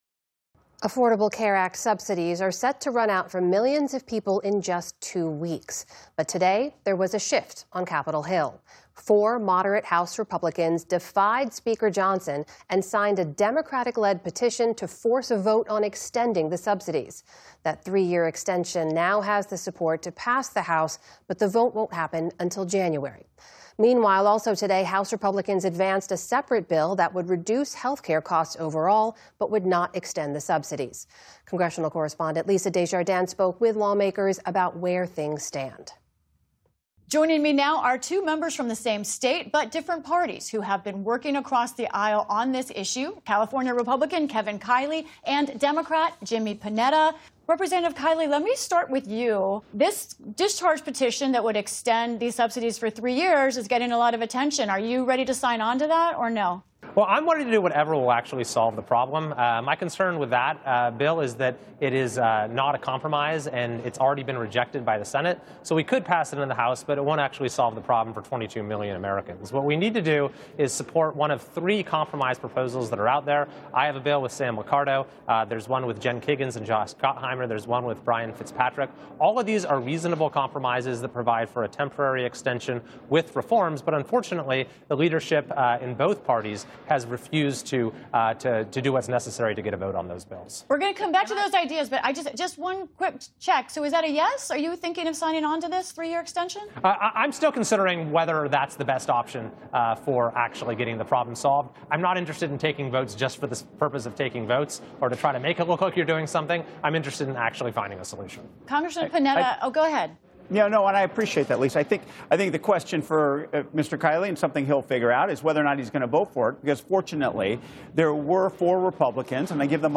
A small group of Republicans defied Speaker Johnson to join with Democrats on a possible three-year extension. Lisa Desjardins discusses where things stand with Republican Rep. Kevin Kiley a…